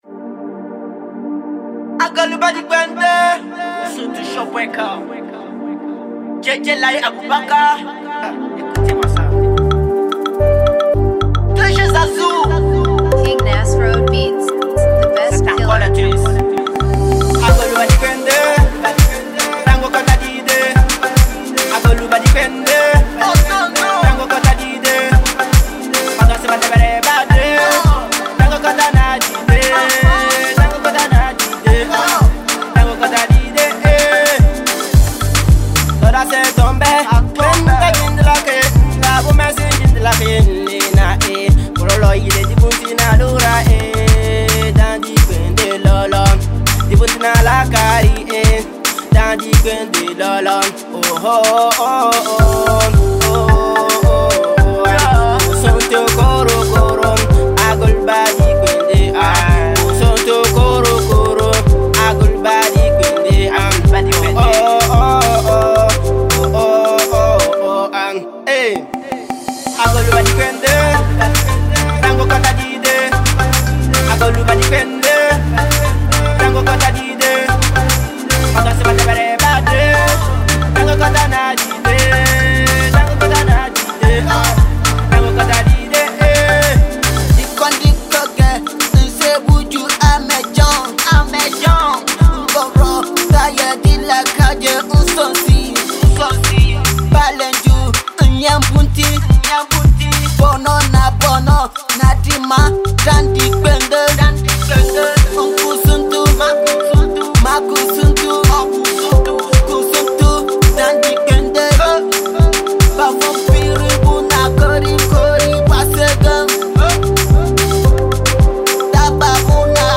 Les jeunes Stars de koussountou sont les jeunes étudiants qui ont chantés a l'honneur de leur village et lance un appel à toute la population au developpement de notre village sans distinction. Nous devrons être uni pour développer notre culture et le mettre en valeur au yeux du monde entier.